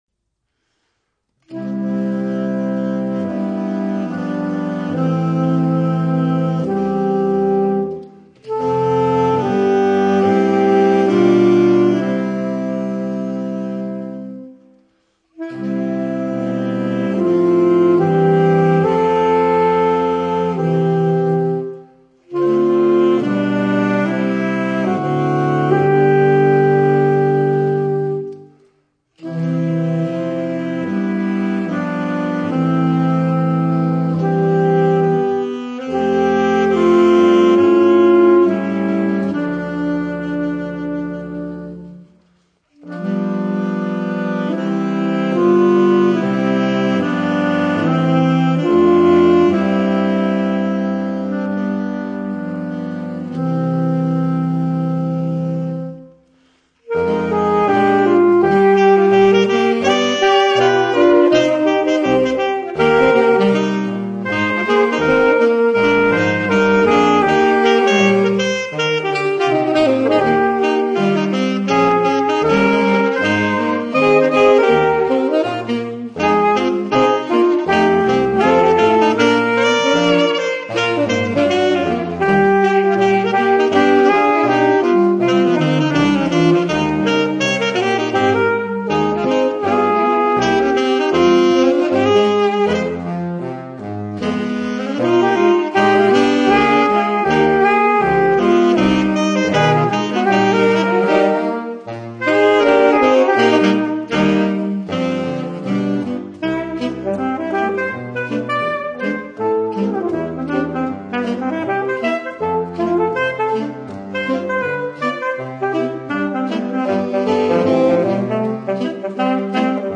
For Woodwinds